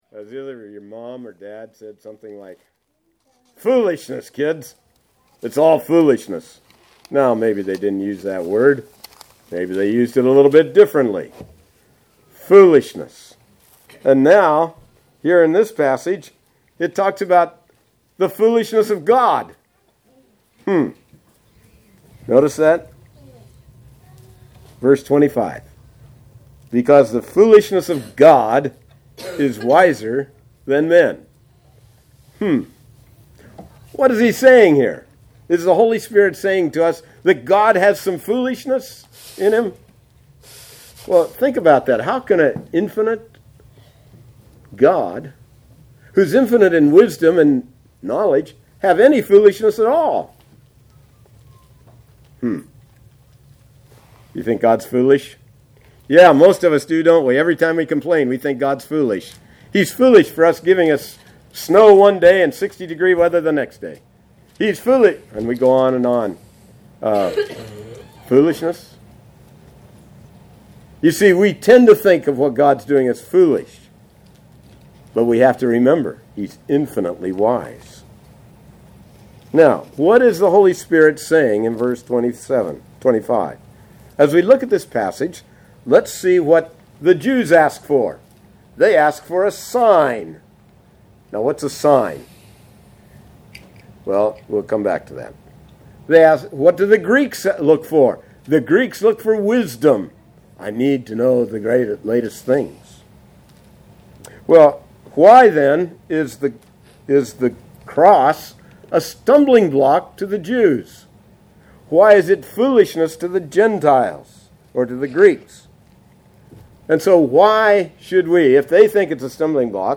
Morning Worship
1 Corinthians 1:25 Service Type: Sunday Morning « 1 Corinthians Series Romans 1